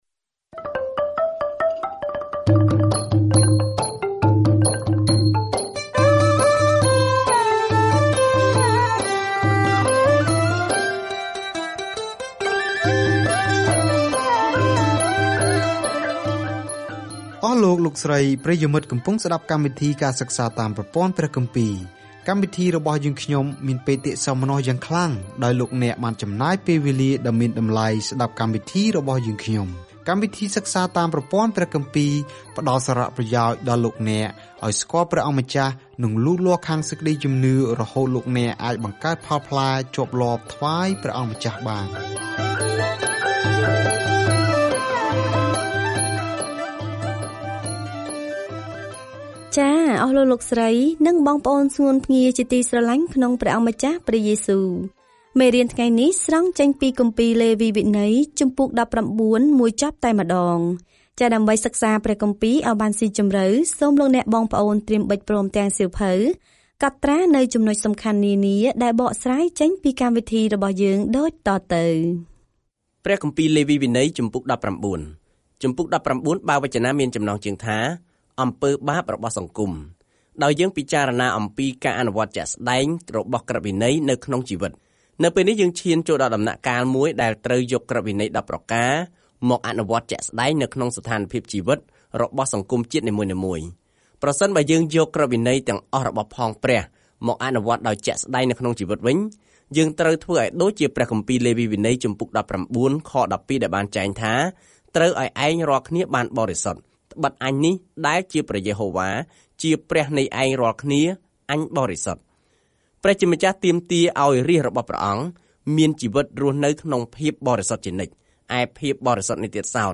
ក្នុងការគោរពប្រណិប័តន៍ យញ្ញបូជា និងការគោរព លេវីវិន័យឆ្លើយសំណួរនោះសម្រាប់ជនជាតិអ៊ីស្រាអែលពីបុរាណ។ ការធ្វើដំណើរជារៀងរាល់ថ្ងៃតាមរយៈលេវីវិន័យ នៅពេលអ្នកស្តាប់ការសិក្សាជាសំឡេង ហើយអានខគម្ពីរដែលជ្រើសរើសពីព្រះបន្ទូលរបស់ព្រះ។